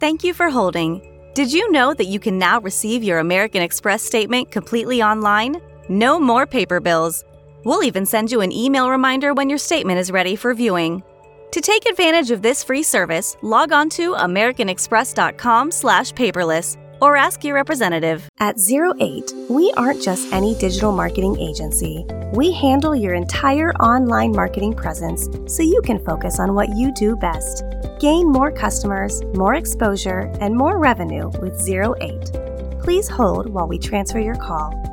Female Voice Over, Dan Wachs Talent Agency.
Warm, young, current
Phone - IVR